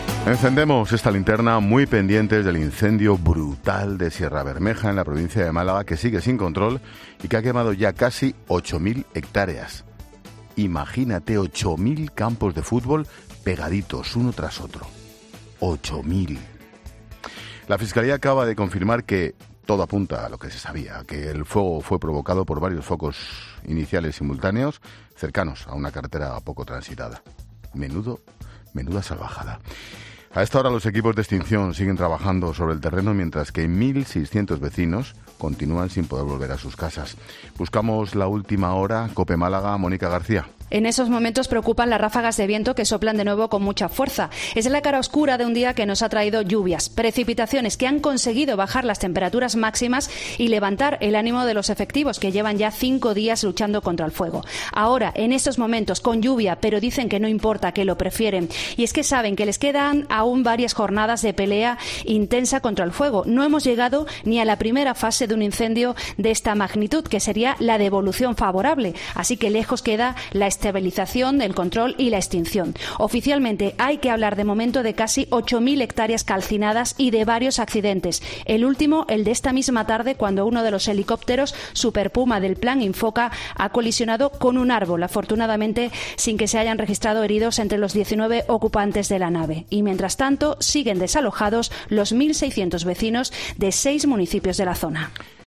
El director de 'La Linterna' ha actualizado la información sobre la evolución de la extinción del fuego
Un asunto en el que se ha centrado el director de 'La Linterna', Ángel Expósito, quien ha arrancado su programa de este lunes asegurando estar "muy pendientes" del incendio "brutal" de Sierra Bermeja, en la provincia de Málaga.